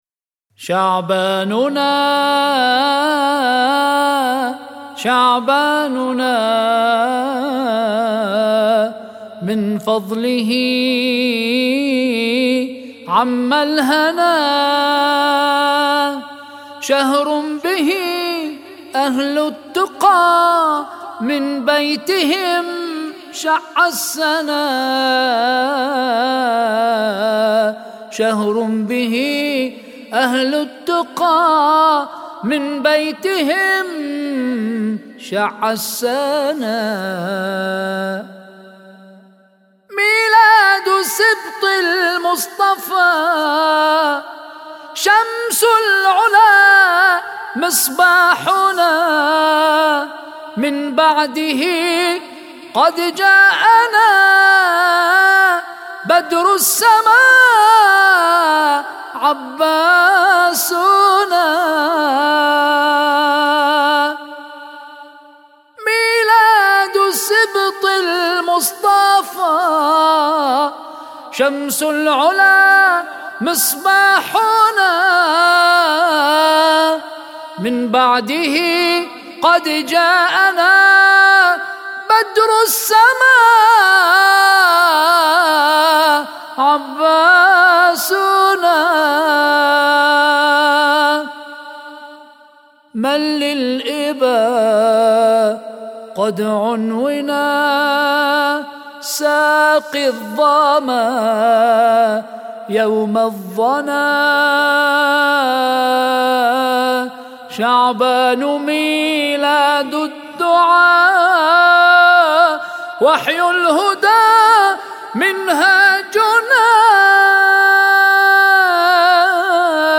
مدائح بمناسبة ذكرى ولادة الإمام الحسين (ع)